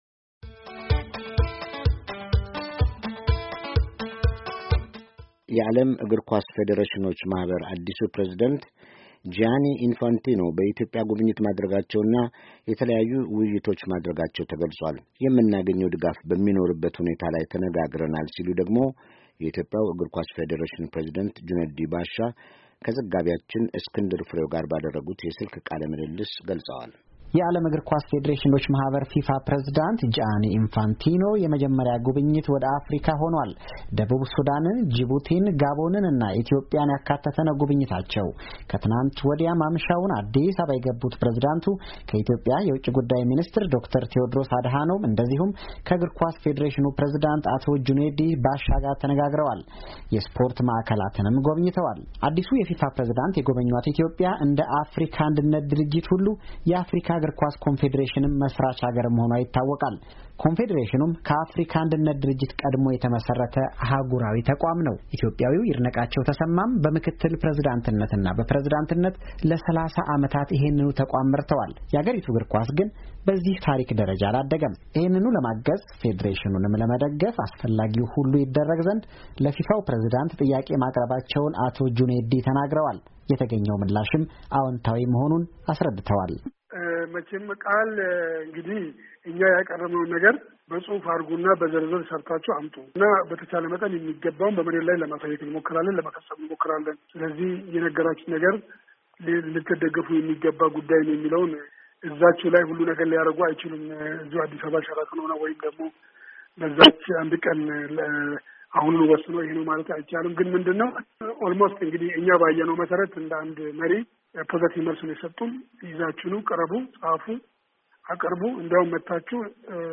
የስልክ ቃለ ምልልስ